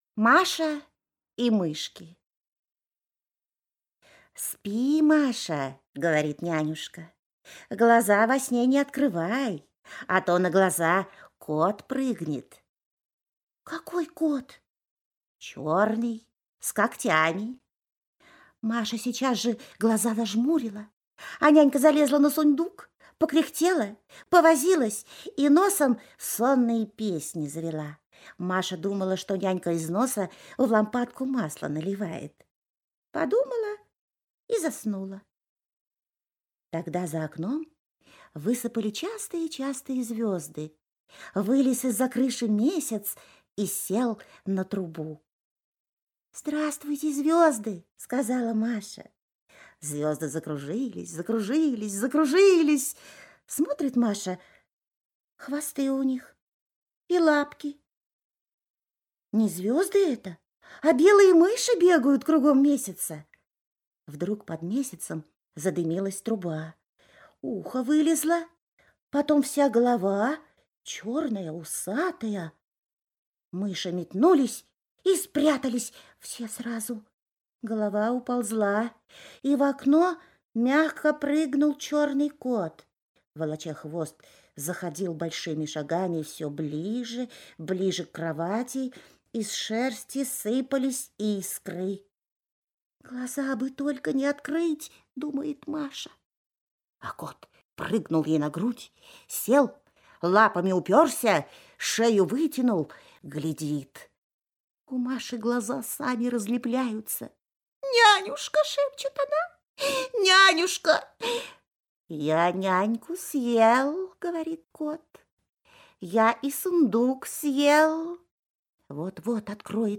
Маша и мышки – Толстой А.Н. (аудиоверсия)
Аудиокнига в разделах